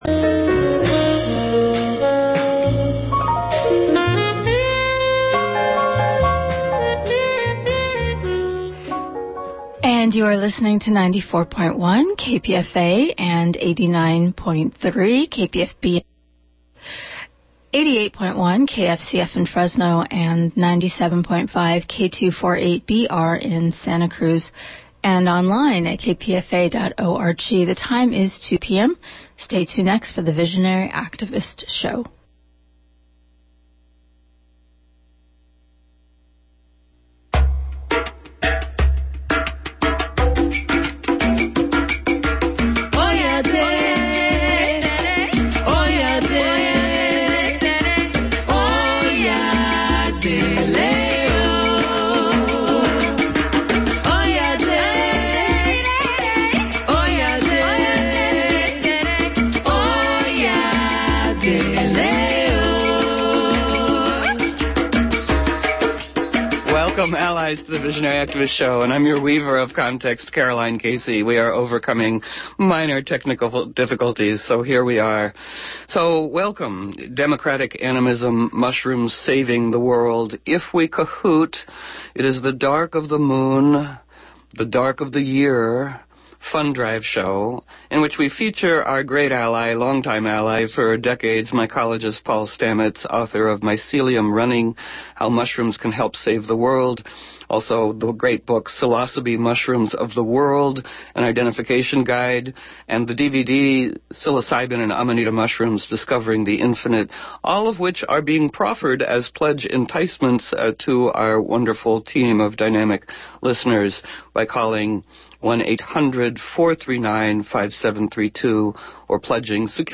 Dark of Moon-Dark of Year-Fund Drive Show
features mycologist Paul Stamets